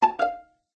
musical_notes_2.ogg